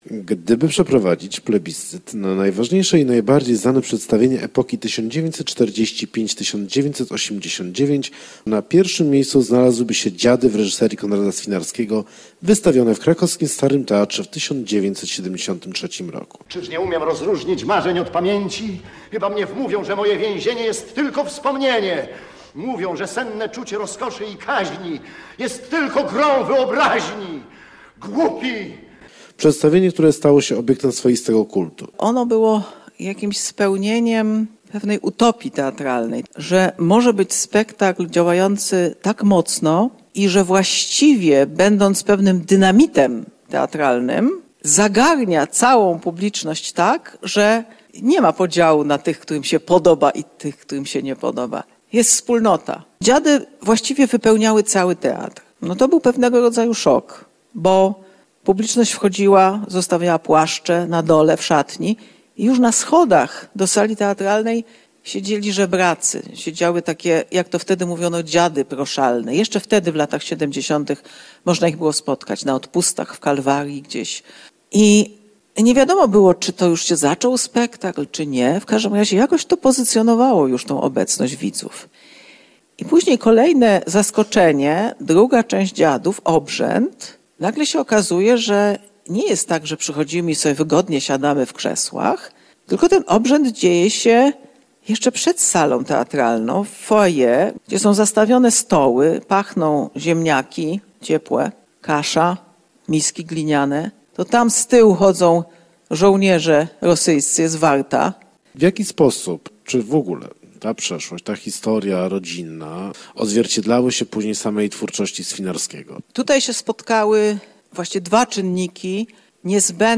w Teatrze Starym w Lublinie
Głosu reżysera – Konrada Swinarskiego i fragmentów jego spektakli wysłuchaliśmy dzięki archiwum Polskiego Radia